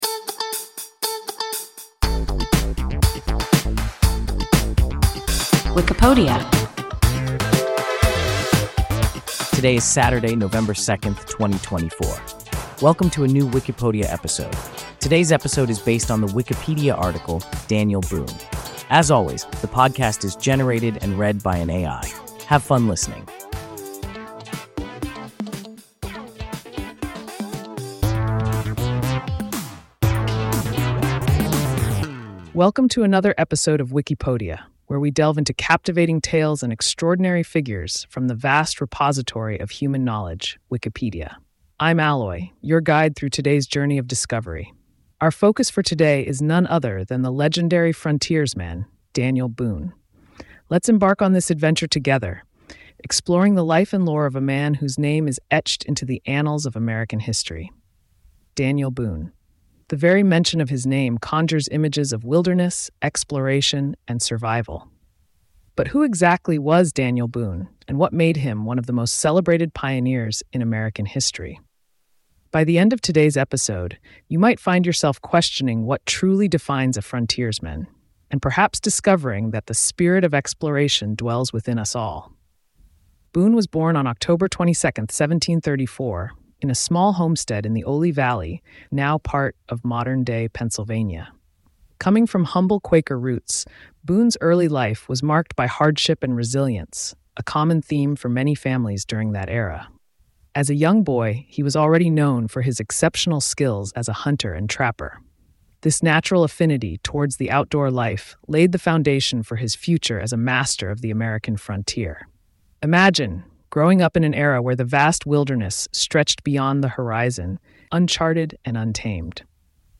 Daniel Boone – WIKIPODIA – ein KI Podcast